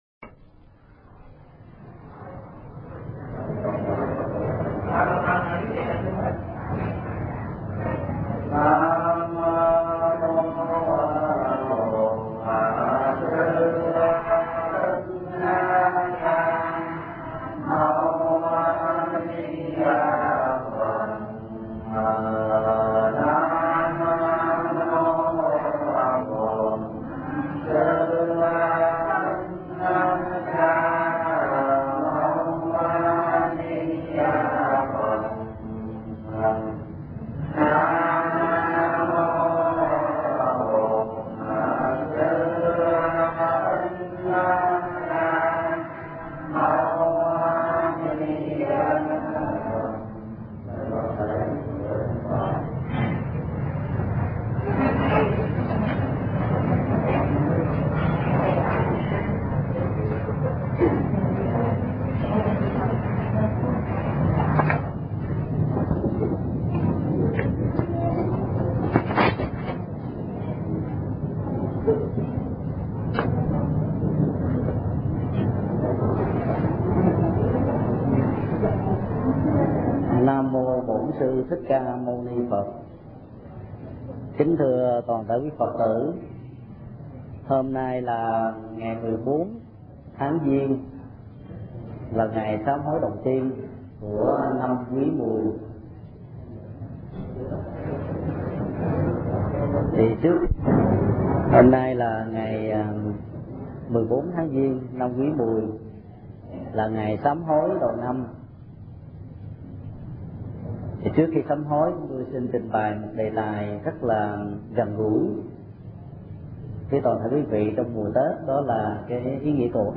Mp3 Thuyết Giảng Cầu an và được an – Thầy Thích Nhật Từ Giảng tại chùa Chùa Giác Ngộ, ngày 19 tháng 2 năm 2004